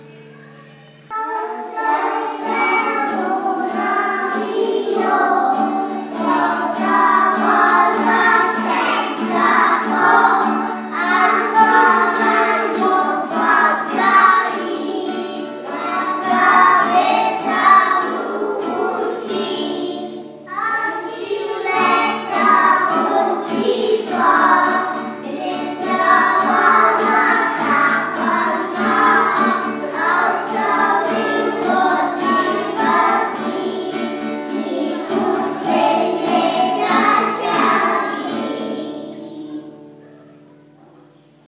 MÚSICA A E. INFANTIL
Els nens i nenes d’educació infantil canten tan i tan bé que els hem gravat perquè els pogueu escoltar des de casa:
• Els de P3 canten “l’avió”